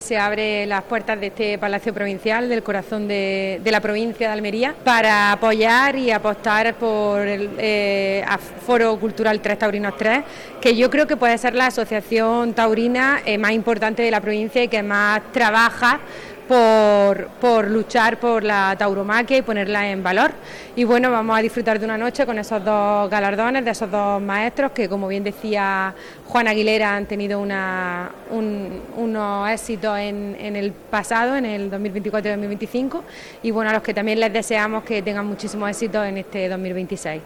La Diputación Provincial de Almería ha acogido la celebración de la gala de entrega de los premios taurinos del Foro Cultural 3 Taurinos 3, correspondientes a la última Feria Taurina de Almería. El acto tuvo lugar en el emblemático Patio de Luces del Palacio Provincial, un espacio que se ha consolidado como punto de encuentro para iniciativas culturales y sociales de relevancia en la provincia.
14-01_toros_diputada.mp3